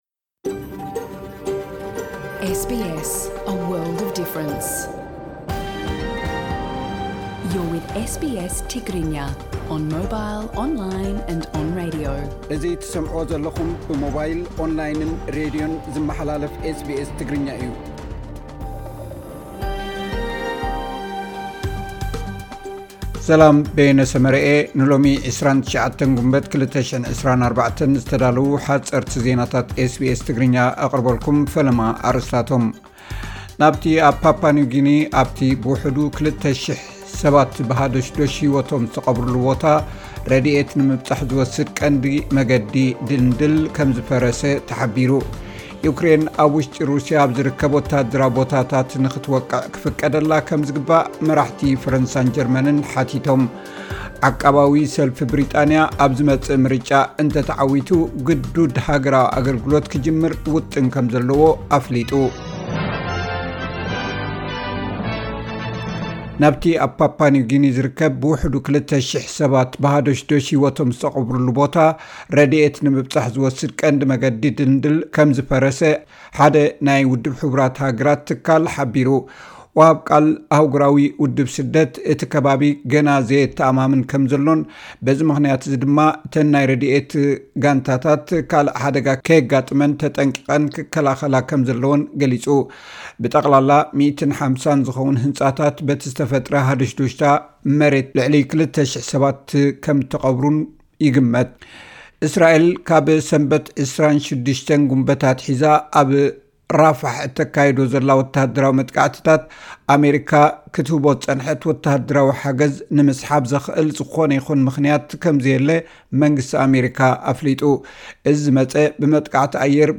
ዕለታዊ ዜናታት ኤስ ቢ ኤስ ትግርኛ (29 ግንቦት 2024)